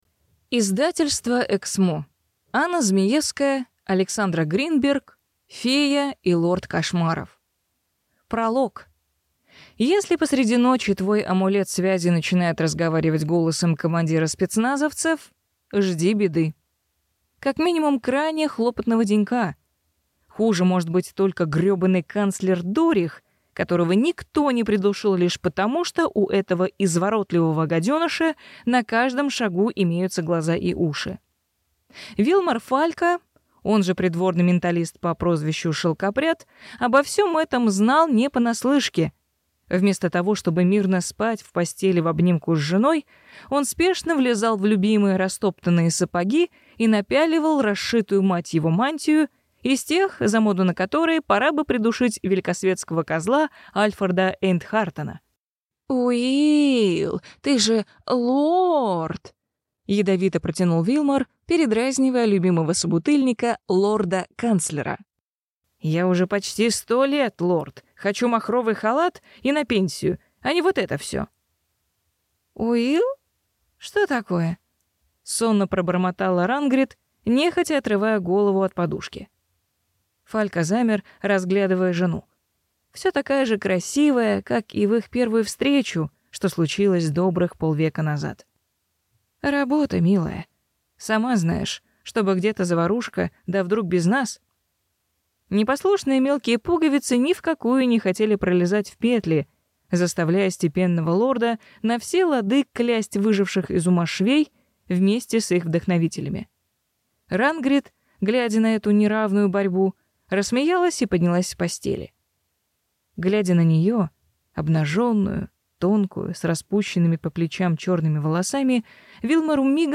Аудиокнига Фея и лорд кошмаров | Библиотека аудиокниг